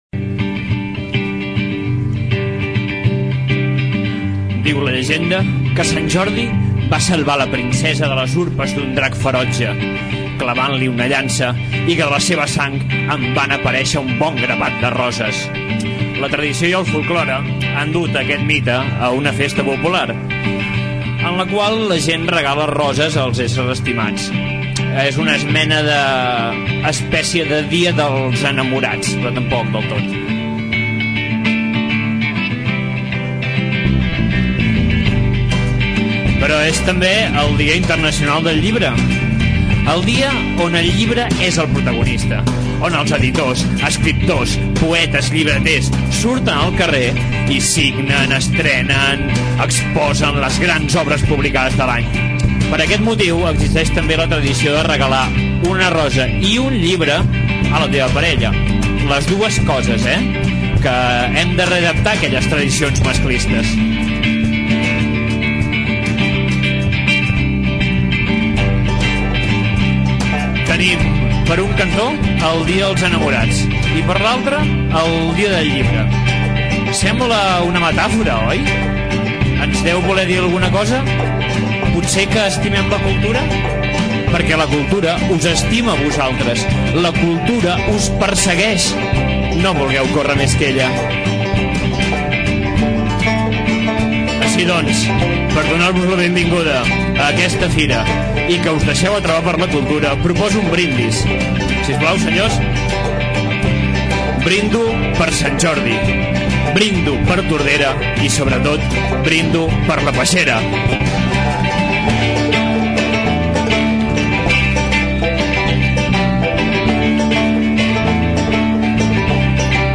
Enguany, els encarregats de preparar el manifest de Sant Jordi van ser els integrants del grup de música local Tardes de Dimarts, que interpreten poesia musicada. Ahir, però, es van convertir en el grup Many Fest. Escoltem un fragment del manifest.
fragment-manifest-sant-jordi.mp3